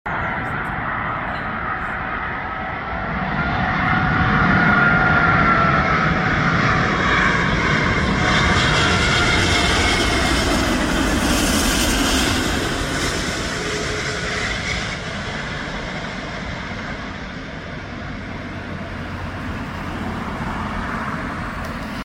Fantastic A380 Landing Heathrow 27L